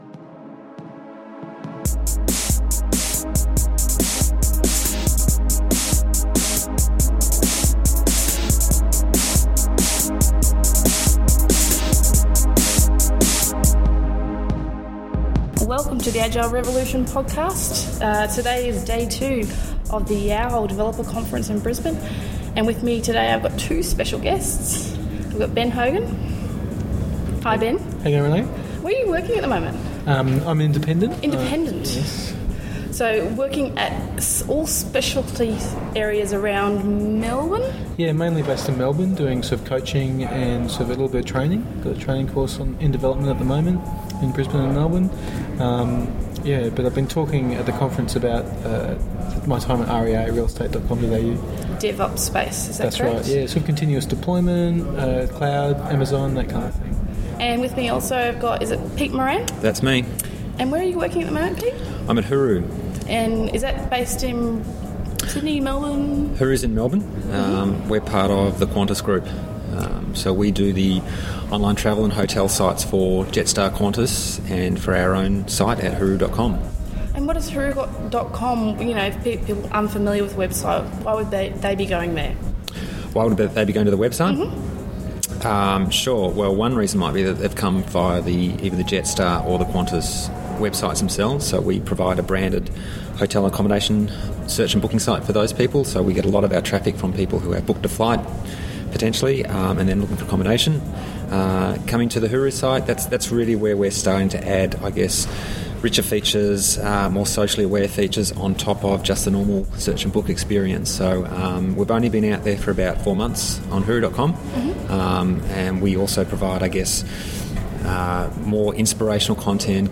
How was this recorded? Thankyou to the YOW! 2012 conference for inviting The Agile Revolution to record podcast interviews at the conference!